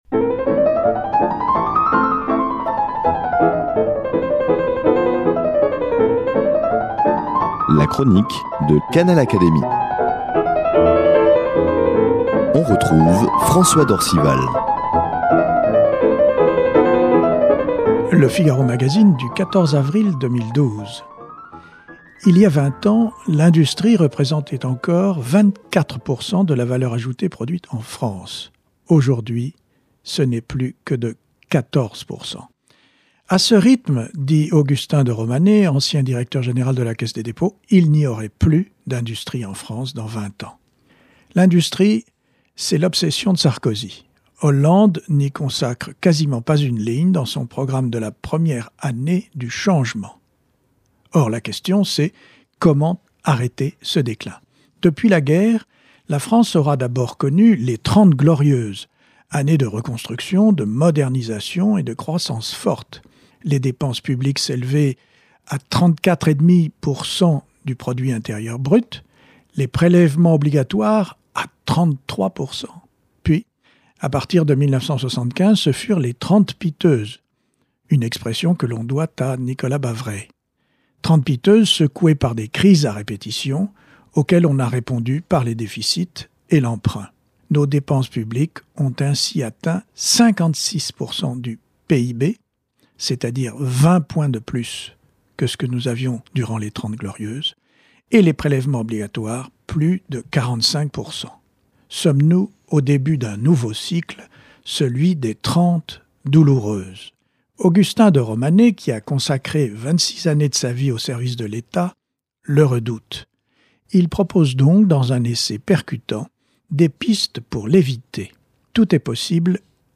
Toujours d’actualité ... la chronique de François d’Orcival de l’Académie des sciences morales et politiques